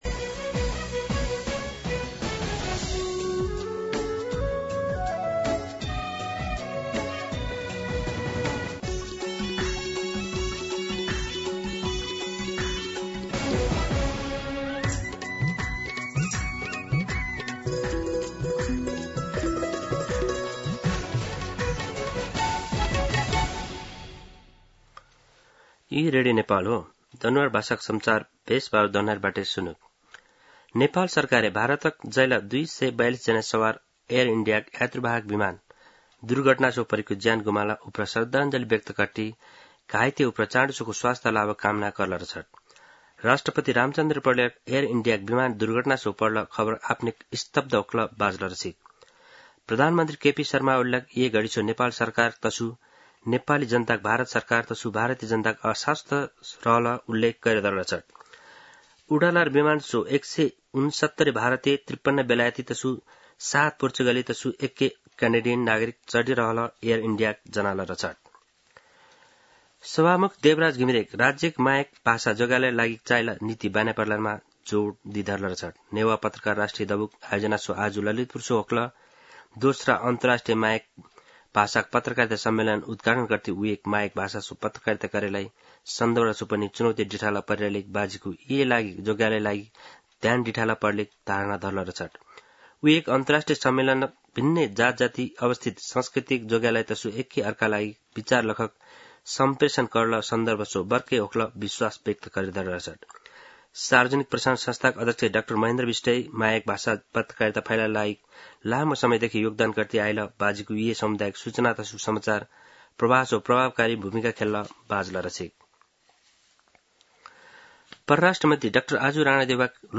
दनुवार भाषामा समाचार : ३० जेठ , २०८२